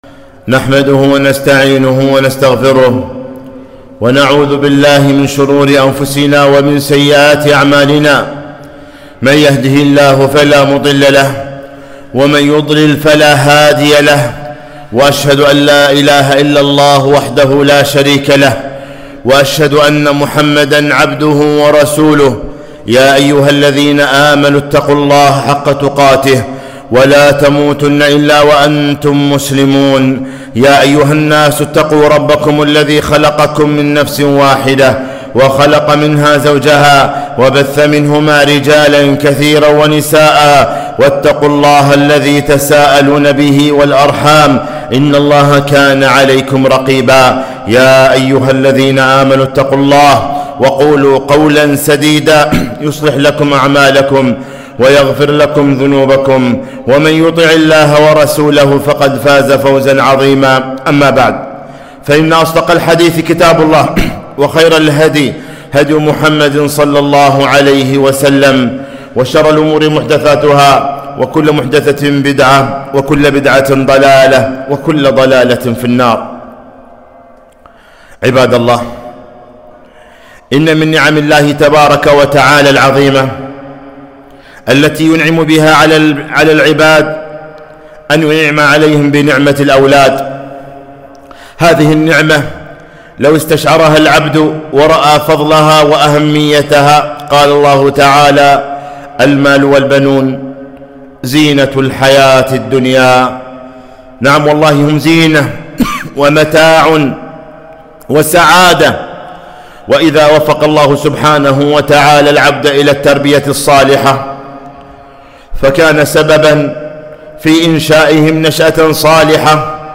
خطبة - أولادكم أمانة في أعناقكم